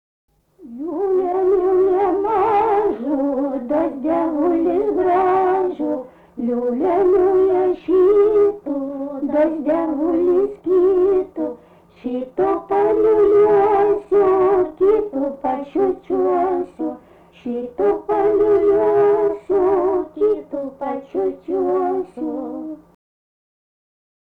Subject daina
Erdvinė aprėptis Anykščiai
Atlikimo pubūdis vokalinis